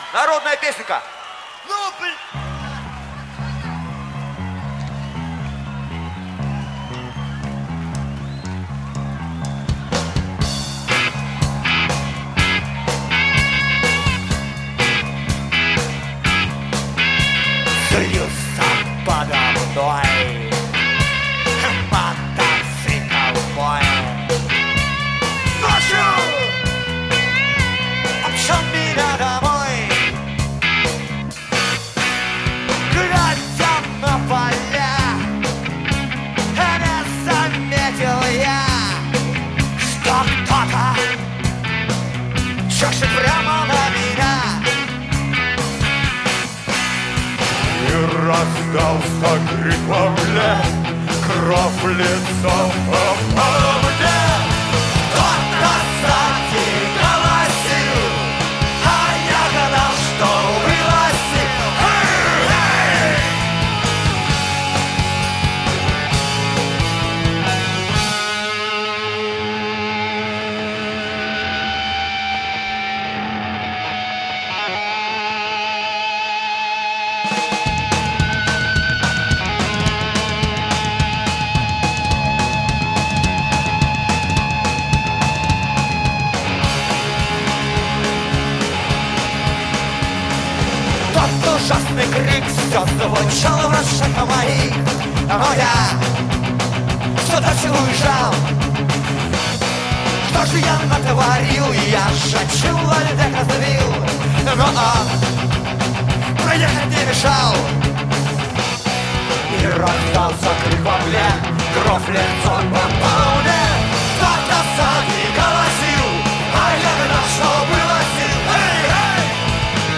Метал
Жанр: Метал / Рок